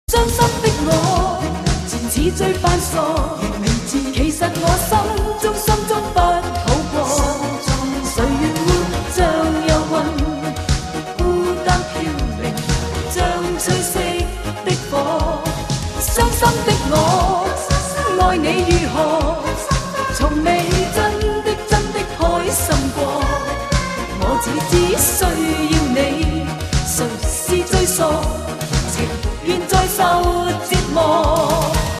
经典好听
粤语